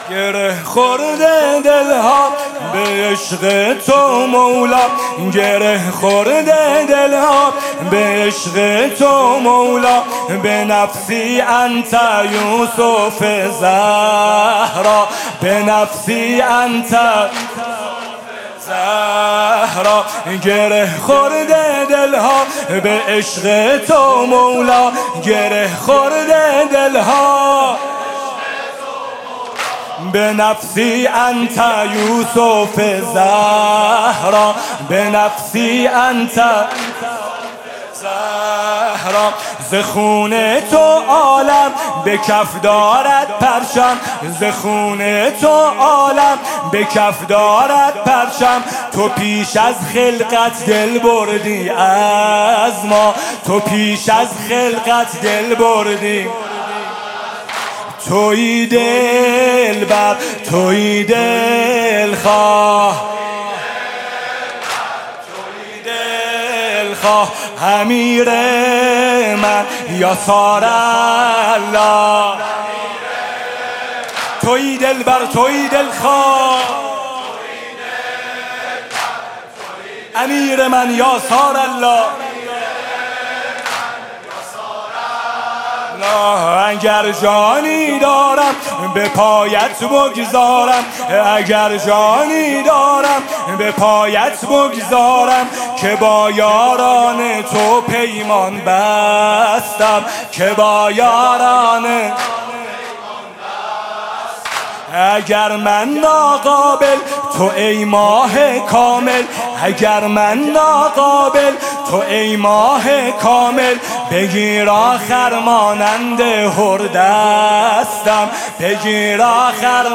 music-icon واحد: گره خورده دل ها به عشق ثارالله